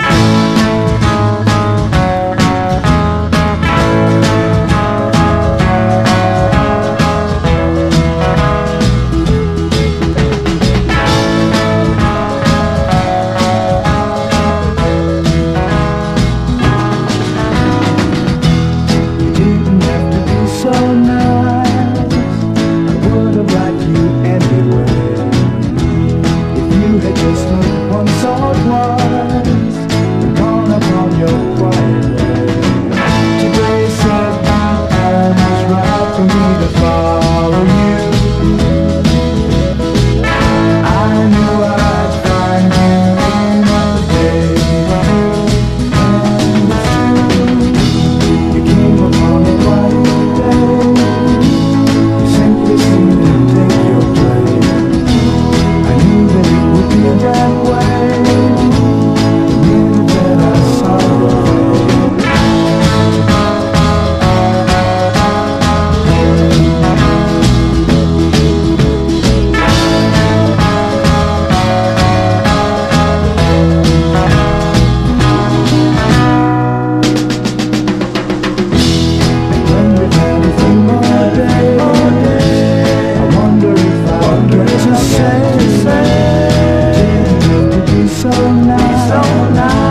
ROCK / 60'S / PSYCHEDELIC ROCK / GARAGE ROCK / FREAK BEAT